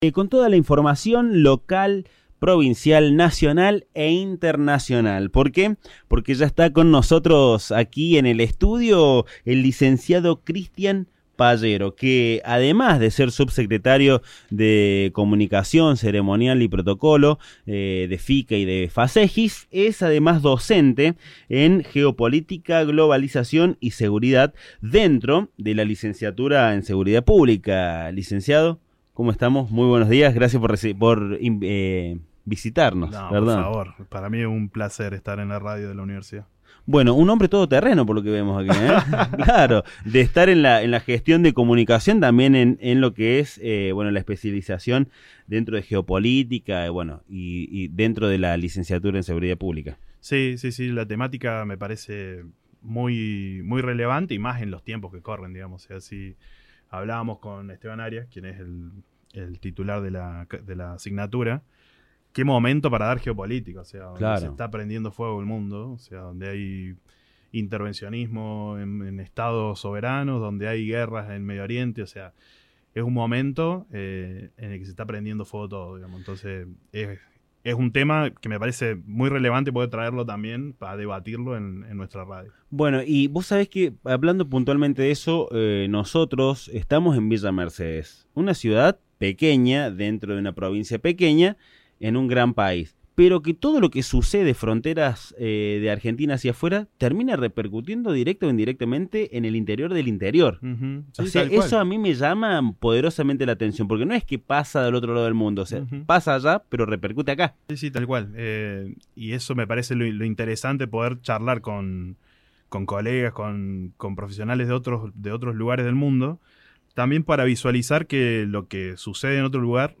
Durante el programa a través de comunicación telefónica dialogamos con el abogado y analista político
Durante la entrevista también se destacó que el cambio político en Chile forma parte de un fenómeno regional , con el avance de gobiernos de derecha en varios países de América Latina, lo que reconfigura el mapa político del continente.